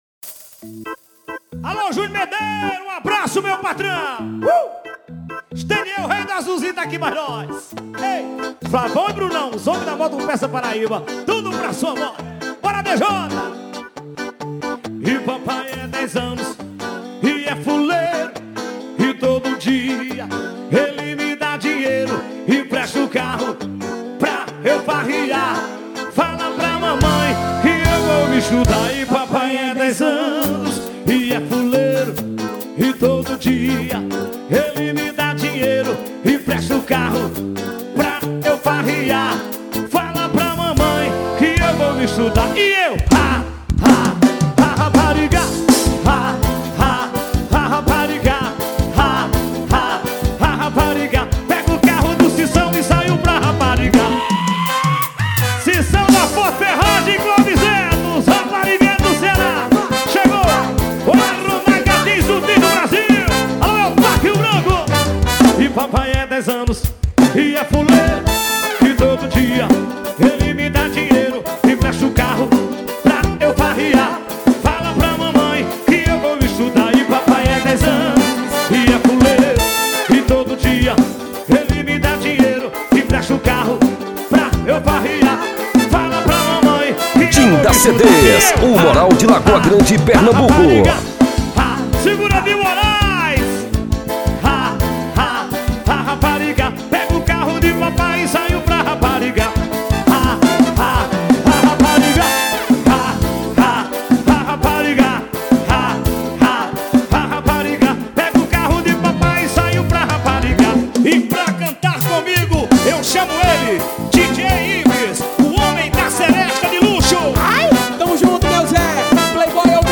Forró de paredão